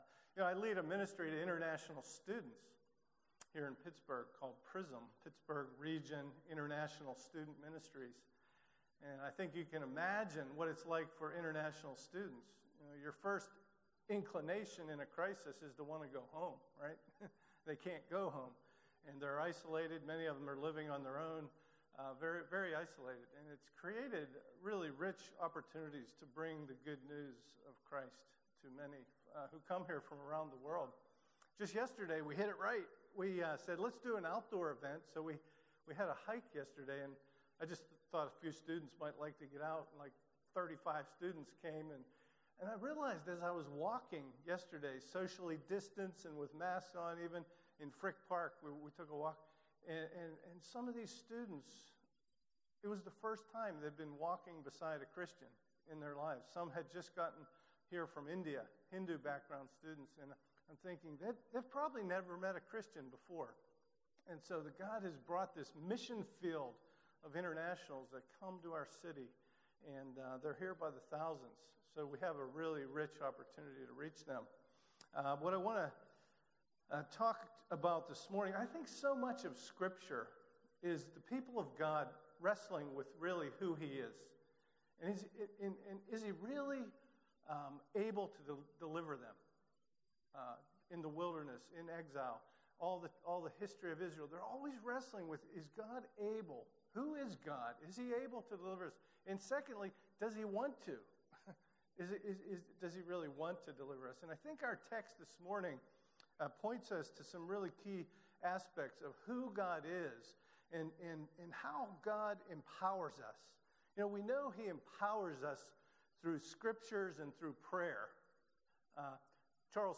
Home New Here Events About Our Beliefs Next Steps Meet our Team Ministries Bless Every Home Kids Youth Women Men Senior Adults Prayer Give Contact Previous Sermons How Long Will You Waiver?